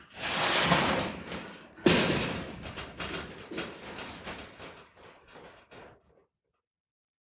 金属 " 长金属刮擦06
描述：金属撞击，隆隆声，擦伤。原声是一扇棚门。
Tag: 光泽 铁匠 金属 工业 钢铁 隆隆声 指甲 命中 打击乐器 金属 刮去 工厂 冲击 锁定 工业